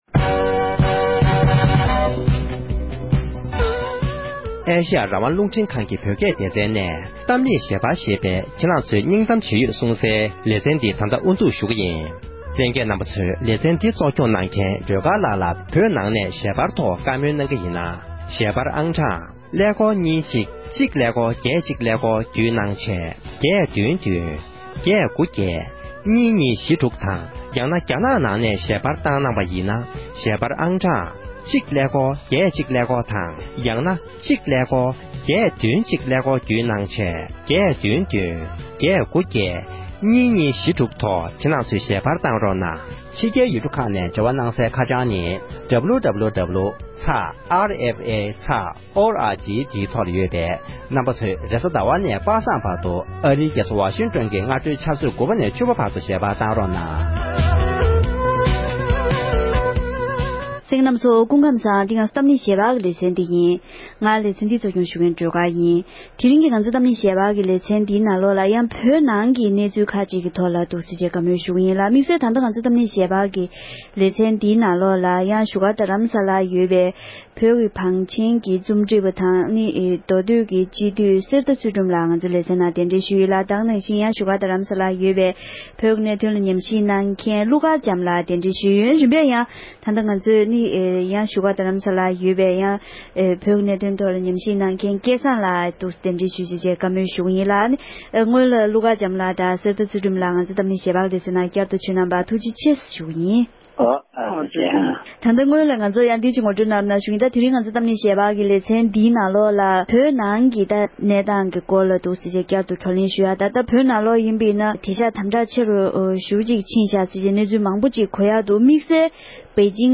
༄༅༎དེ་རིང་གི་གཏམ་གླེང་ཞལ་པར་གྱི་ལེ་ཚན་ནང་དུ་པེ་ཅིང་གི་ཨོ་ལིམ་པིཀ་ལུས་རྩལ་འགྲན་ཚོགས་འཇུག་སྒྲིལ་བྱས་པའི་རྗེས་བོད་ནང་དམ་བསྒྲགས་བྱེད་ཤུགས་ཆེ་རུ་གཏང་གི་ཡོད་མིན་གྱི་འབྲེལ་ཡོད་གནས་ཚུལ་ཁག་གི་ཐོག་བགྲོ་གླེང་ཞུས་པ་ཞིག་གསན་རོགས་གནང༌༎